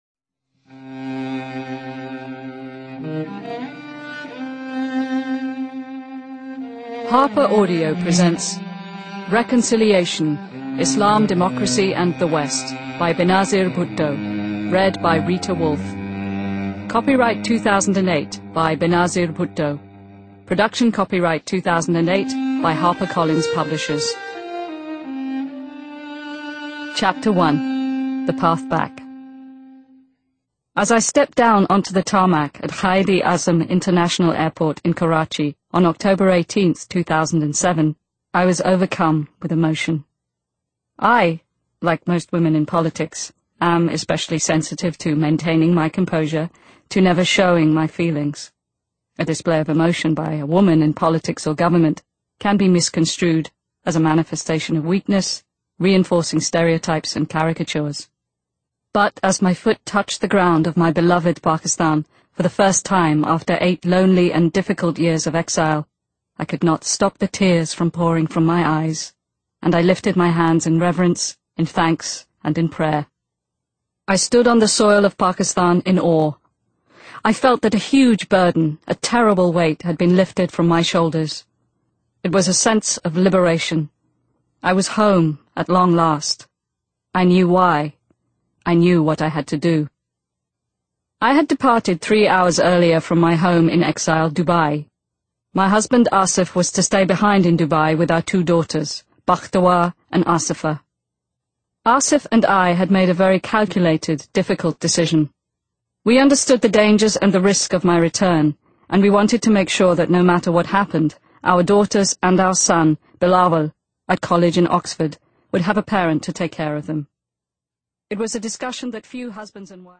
Audiobook - Reconciliation